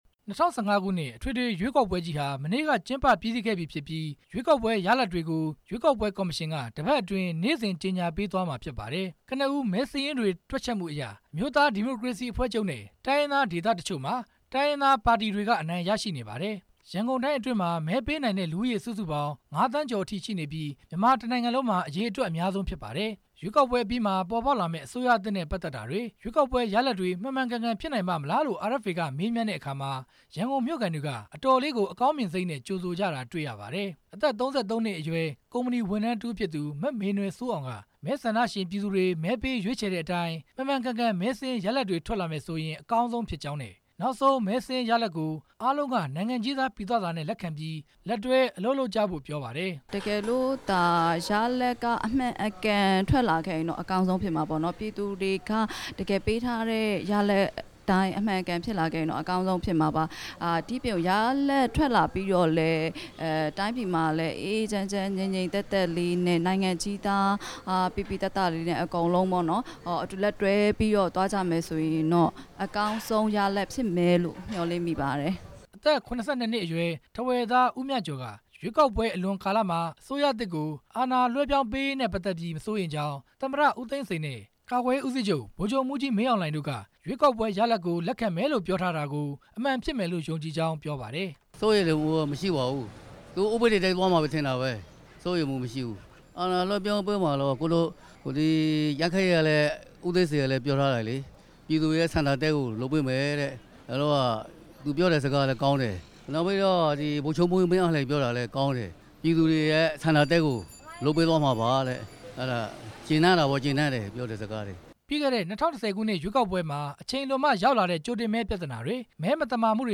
ရန်ကုန်မြို့ခံတွေရဲ့ အမြင်